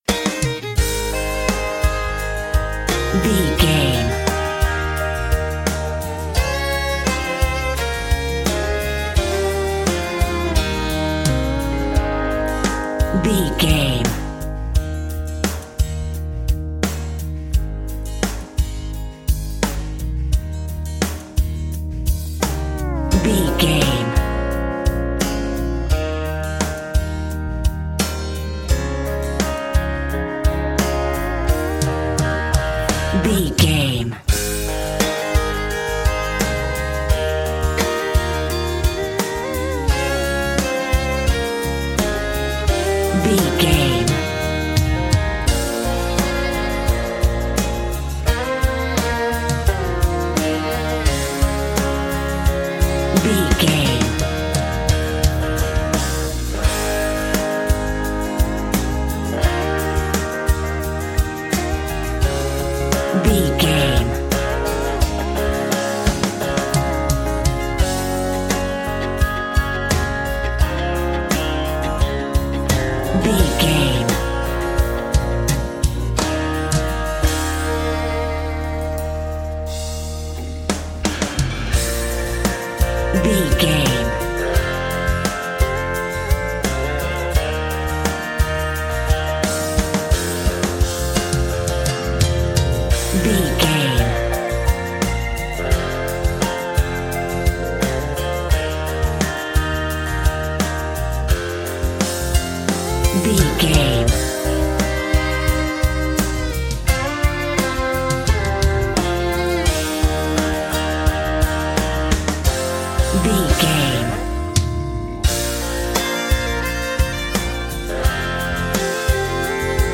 Ionian/Major
A♭
acoustic guitar
electric guitar
drums
violin